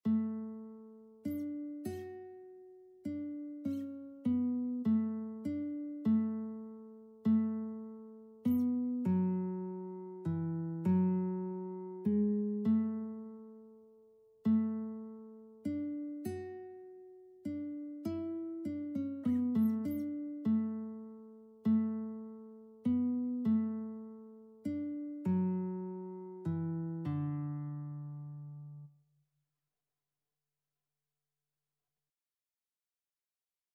Christian Christian Lead Sheets Sheet Music In the Cross of Christ I Glory
3/4 (View more 3/4 Music)
D major (Sounding Pitch) (View more D major Music for Lead Sheets )
Classical (View more Classical Lead Sheets Music)